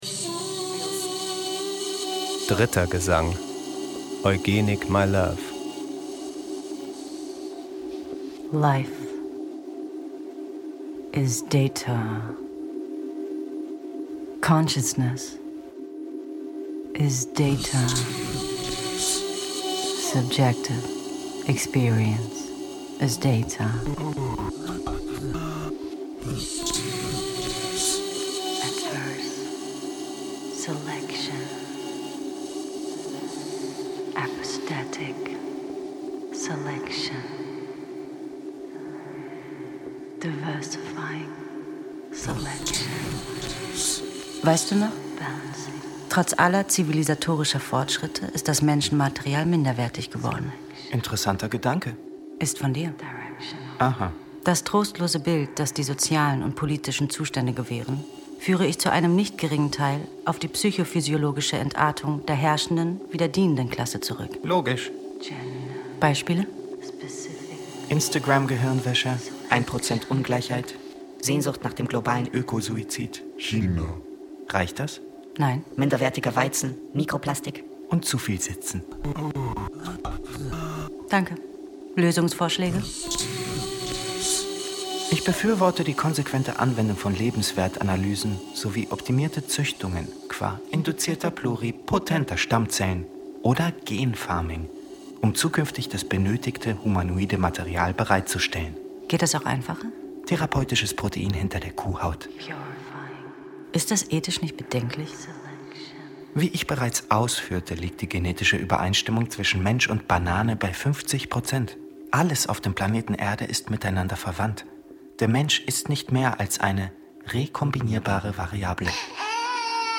Tell Me Something Good, Stockhausen! (3D Version, für Kopfhörer optimiert) | BR Hörspielpool | 61 Min.
In dreidimensional angelegten, rein digitalen Gesängen wird die Maschine zur Solistin.
In 12 synthetischen Gesängen steuert uns ein neues Wesen, „Enhance“, durch Beobachtungen aus unserem schizophrenen, medialen, postfaktischen, von disruptiven Technologien und Denkschablonen geprägten Alltag und propagiert die Notwenigkeit des Datazentrismus.
Tell Me Something Good, Stockhausen! wurde als Stereo- und 360˚-Version produziert.